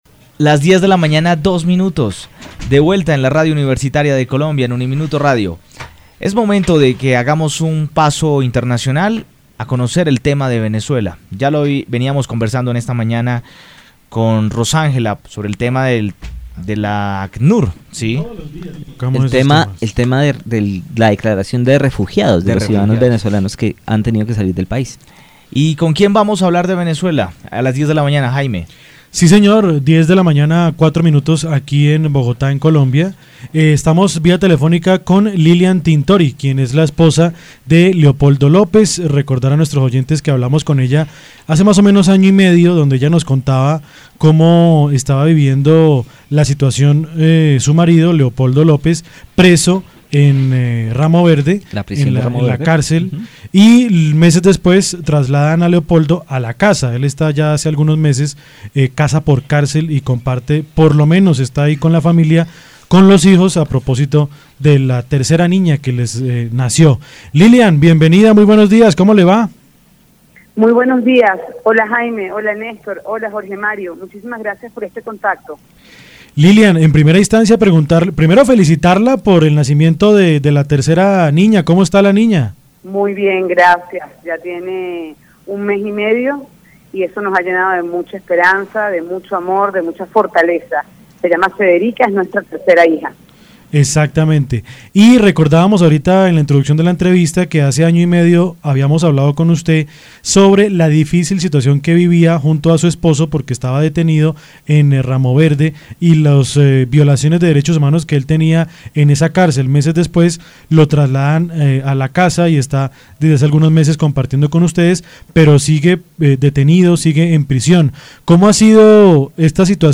En diálogo con UNIMINUTO Radio estuvo Lilian Tintori, esposa del político venezolano detenido Leopoldo López, hablando sobre la difícil situación interna que vive Venezuela y su familia por las presiones del gobierno de Nicolás Maduro.
Entrevista-a-Lilian-Tintori-situación-Venezuela.mp3